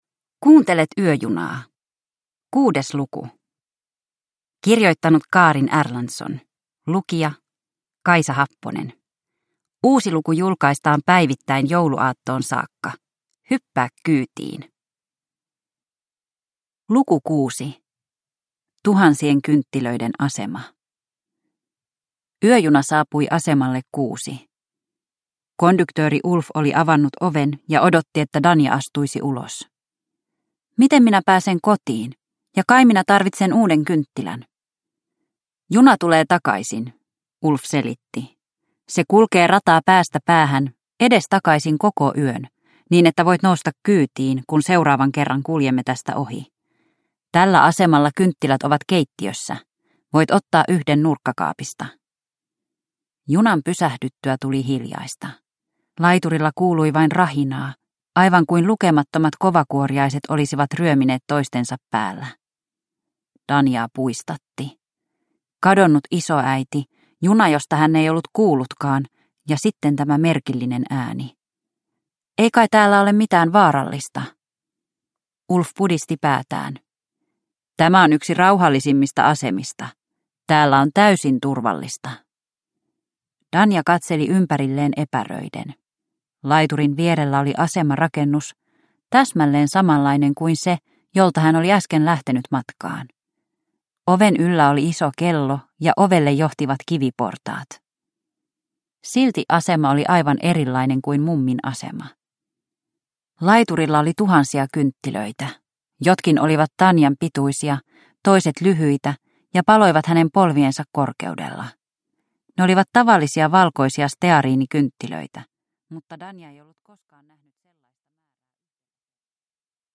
Yöjuna luku 6 – Ljudbok